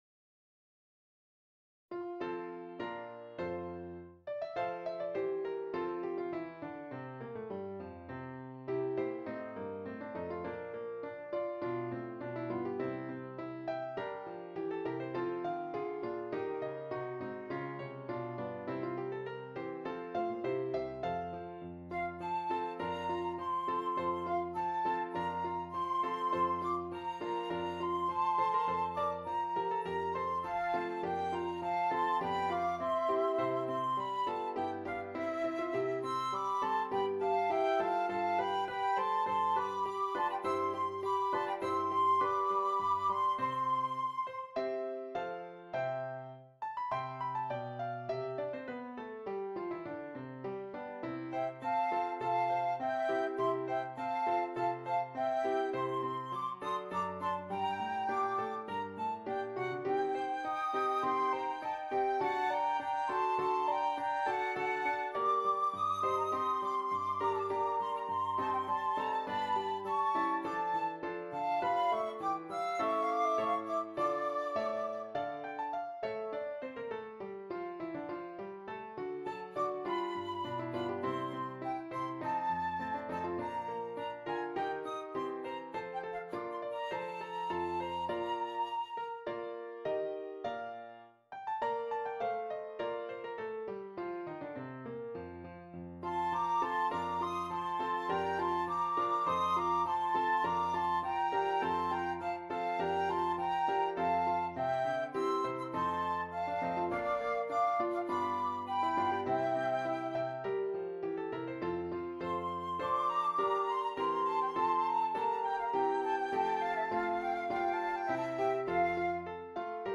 2 Flutes and Keyboard
arranged here for 2 flutes and keyboard (organ or piano)